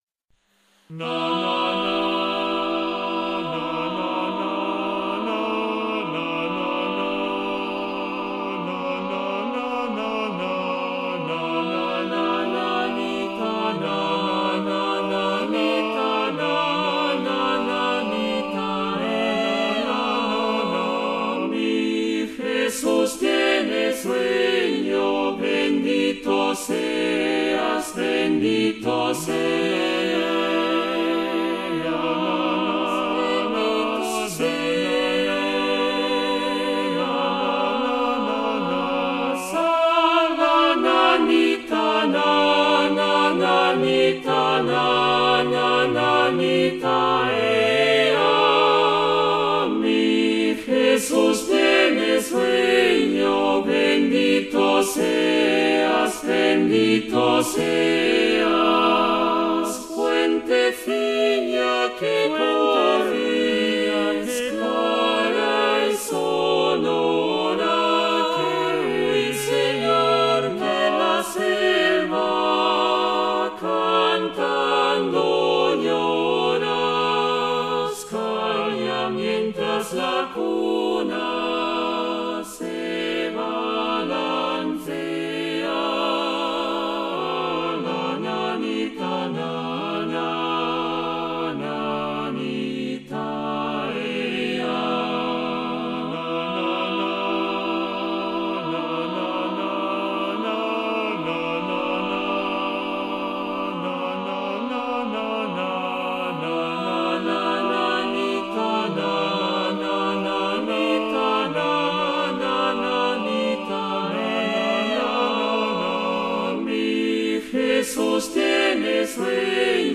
Formato: SATB (divisi)